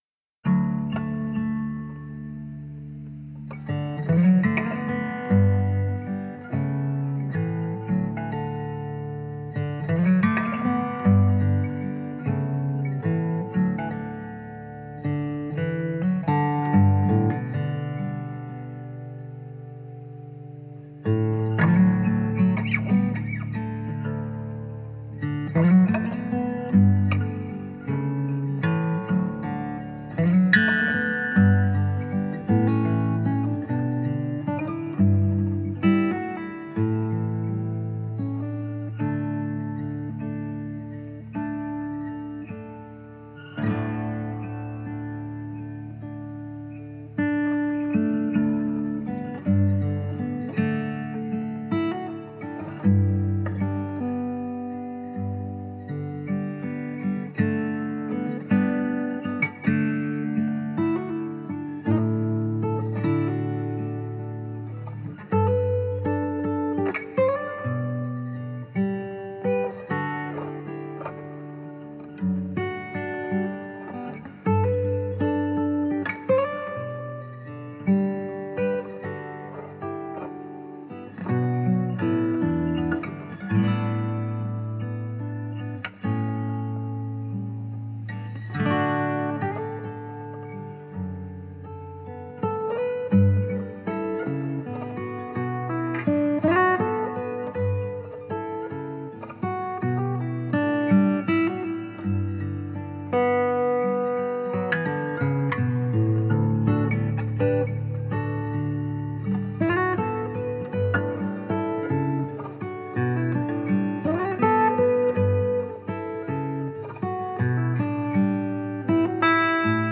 Lady Of The Valley (Irish Folk Gitarre)